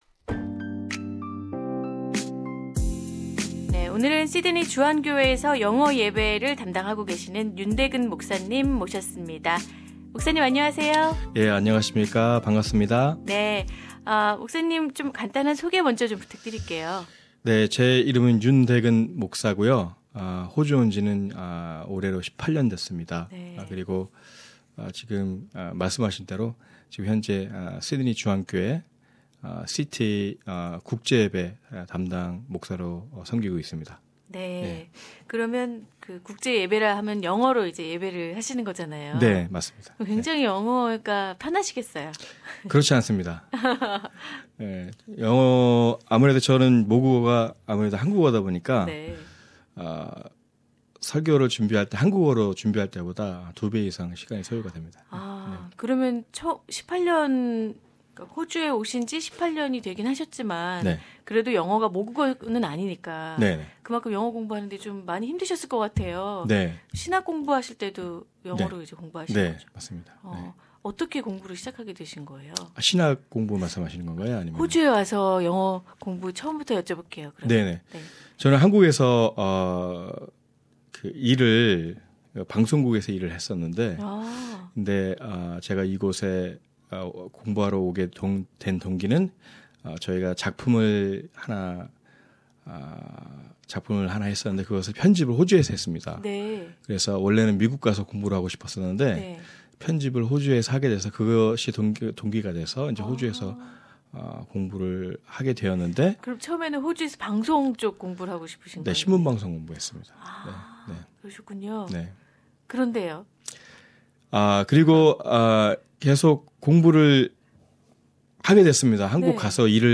at SBS studio